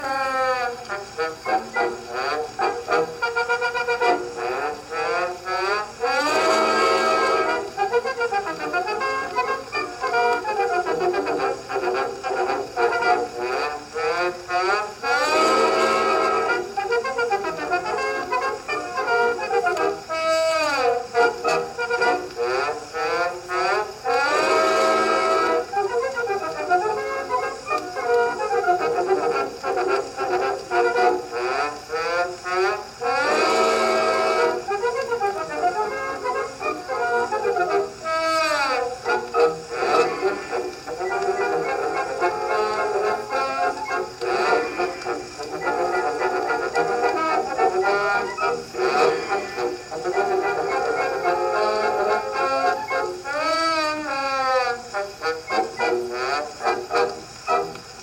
1900s Edison phonograph playing music
1900s carnival crackle Edison historical-sounds history music noise sound effect free sound royalty free Music